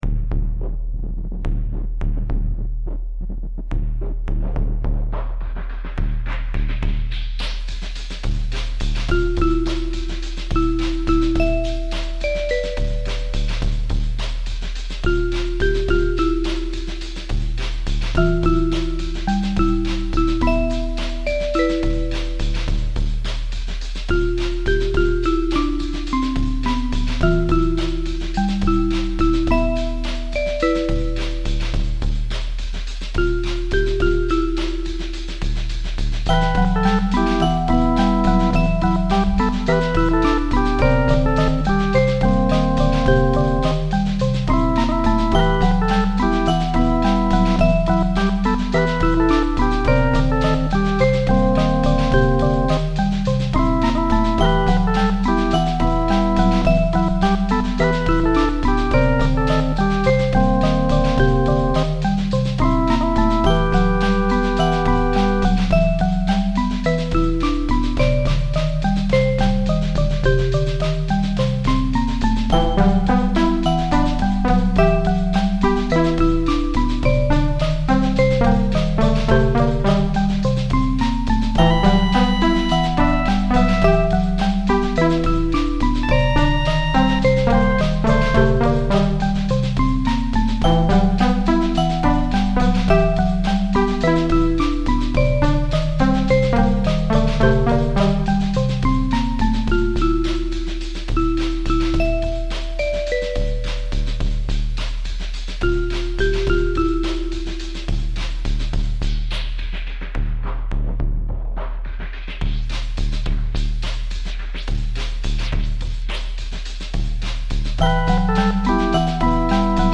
• AE Modular synthesizer rack shown as configured here, notably including three GRAINS modules, a 555 VCO pushed through a Wavefolder, and a PikoCore, among others.
The song is poorly mixed and has limited effects because I'm constrained to do absolutely everything from within the modular synthesizer itself.